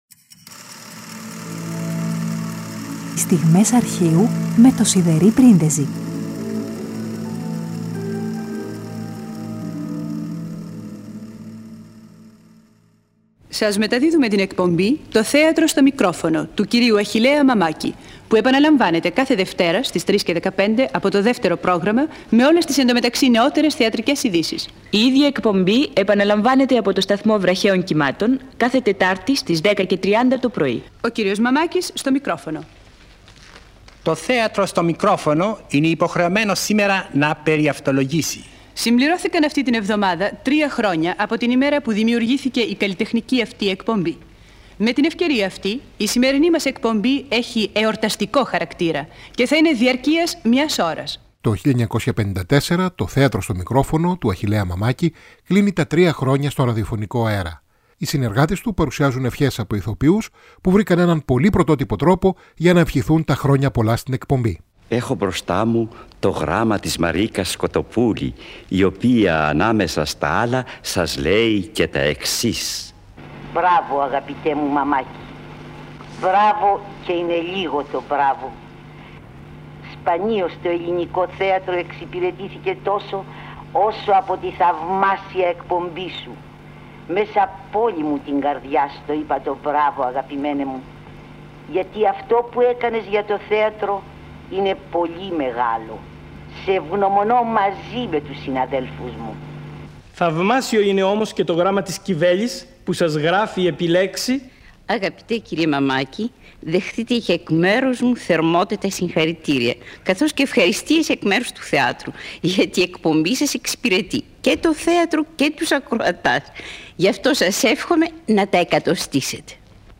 Οι συνεργάτες του παρουσιάζουν ηθοποιούς που βρήκαν έναν πρωτότυπο τρόπο για να στείλουν τις ευχές τους.